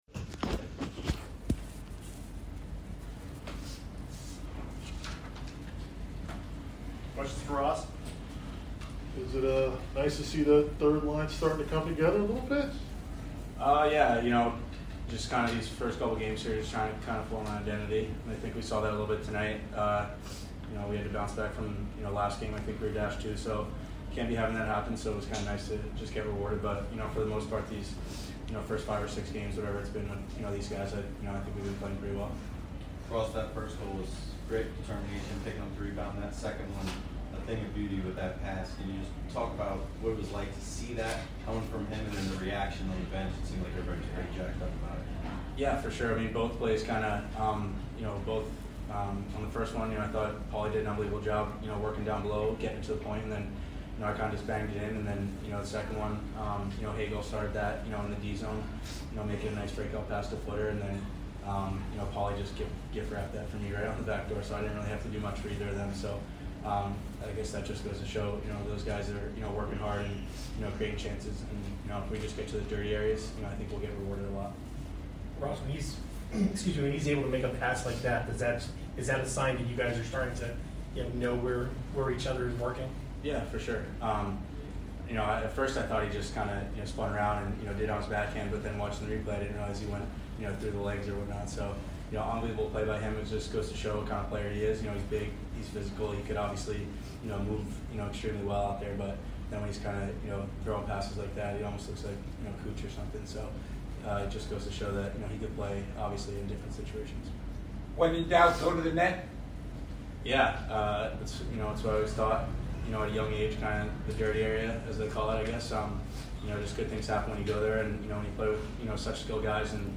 Ross Colton Post Game Vs CHI 4 - 1-2022